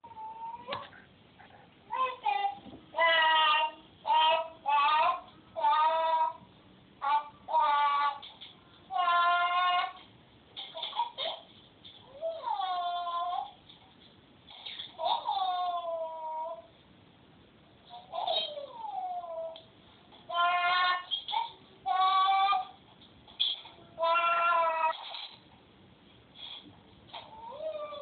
Yari1Yari: Edelpapegaai man
Het is een echte kletser en kan goed zingen en fluiten.
Als hij alleen is vermaakt hij zich heel goed en is dan lekker bezig, luister maar eens naar dit geluidsfragment (